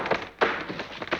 Index of /90_sSampleCDs/E-MU Producer Series Vol. 3 – Hollywood Sound Effects/Water/Falling Branches
LIMB CRAC07L.wav